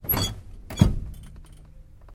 Звук натискання на педаль